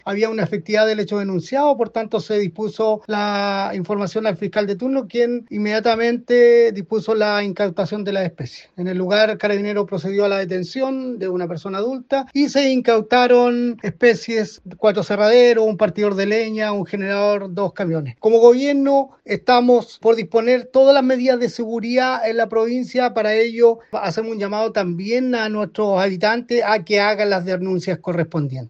El delegado de la Provincia de Arauco, Pedro Marileo, entregó más detalles respecto de lo incautado, condenando los hechos y haciendo un llamado a denunciar este tipo de delitos.